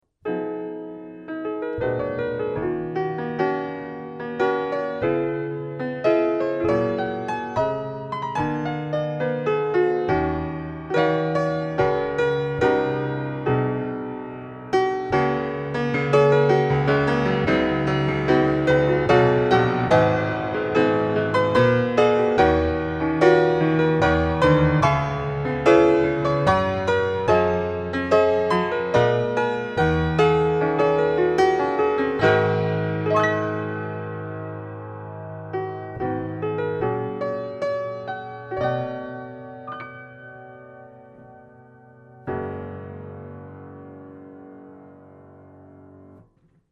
부산의 모 교회에서 ^^ 녹음을 진행하였습니다.
장소는 충분히 넓었고, 피아노의 상태는 매우 좋았고 튜닝도 양호하였습니다.
녹음은 마이크로부터 Lexicon Lambda 오디오 인터페이스로 바로 받았구요.
SM57보다 훨씬 풍부하고 자연스러운 소리라는 것을 알 수 있습니다
고음은 고음인데 뭔가 부족한, 그러면서도 왠지 초고음대가 반짝거리는 소리란 것을 느끼셨나요?